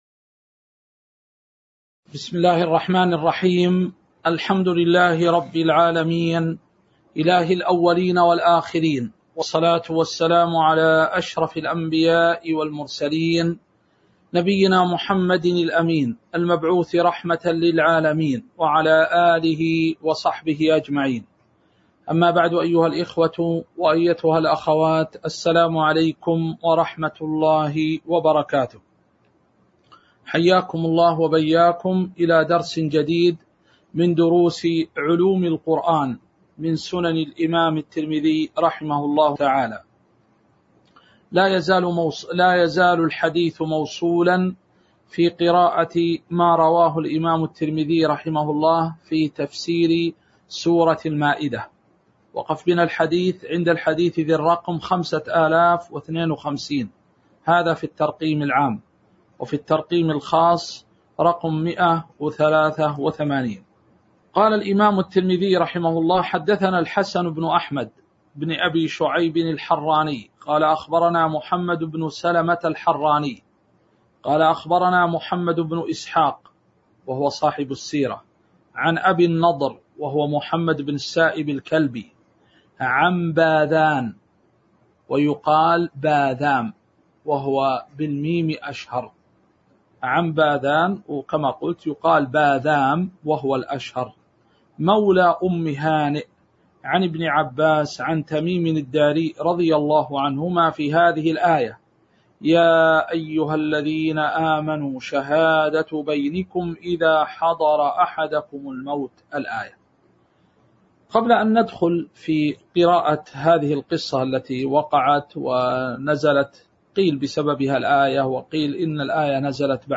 تاريخ النشر ٢ ربيع الثاني ١٤٤٣ هـ المكان: المسجد النبوي الشيخ